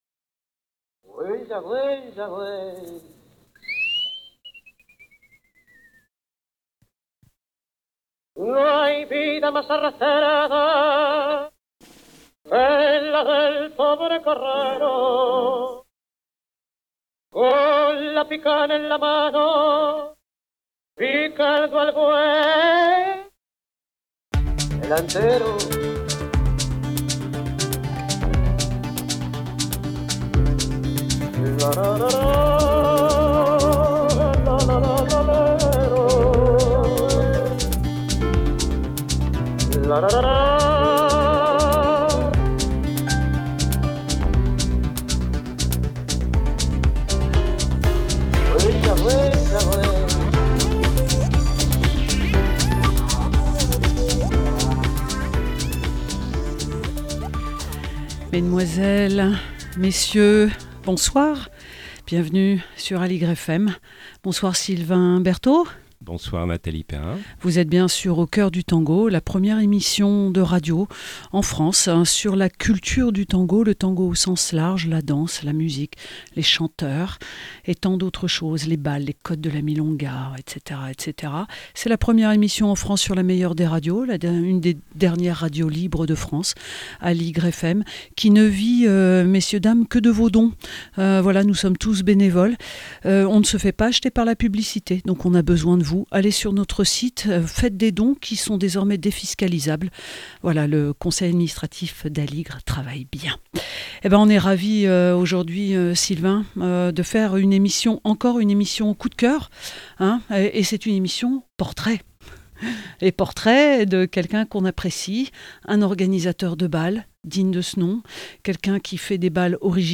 Au fil de la conversation et de quelques extraits musicaux, nous chercherons à parcourir sa carrière autant qu'à rencontrer l'homme et sa sensibilité, danseur, chorégraphe, metteur en scène, écrivain, linguiste, organisateur de bals, artiste graphique...